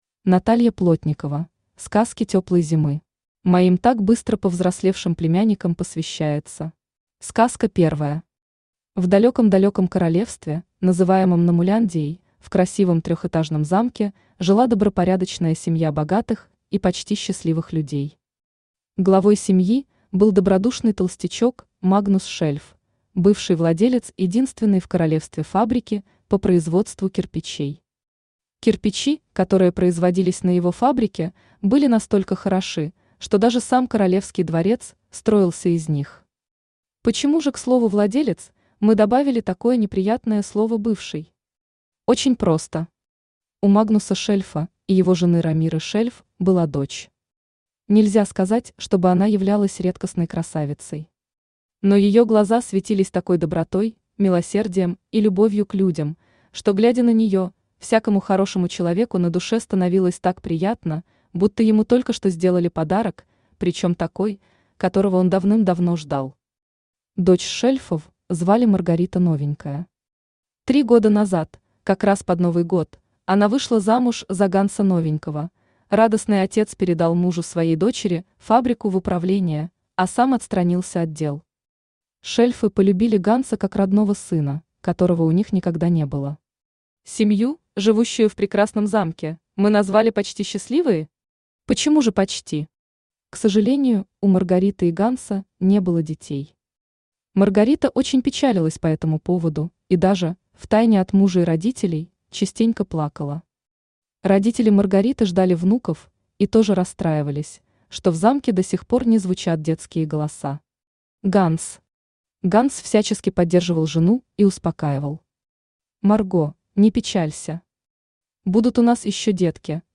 Аудиокнига Сказки теплой зимы | Библиотека аудиокниг
Aудиокнига Сказки теплой зимы Автор Наталья Вадимовна Плотникова Читает аудиокнигу Авточтец ЛитРес.